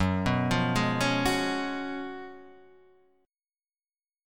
F# Major 7th Suspended 2nd Suspended 4th